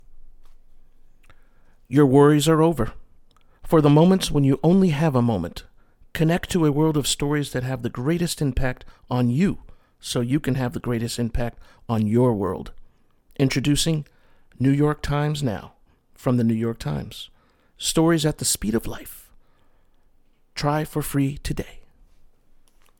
Male
Adult (30-50)
Dynamic voice with versatile range that brings various genres to life.
Radio Commercial
Words that describe my voice are conversational, neutral tone, Middle-aged.